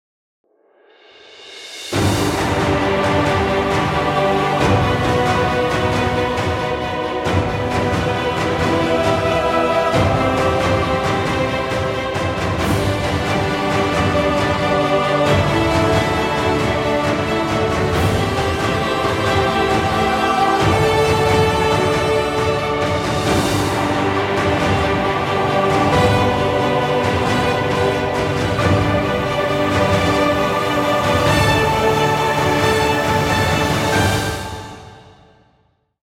Hybrid trailer music, exciting intro, or battle scenes.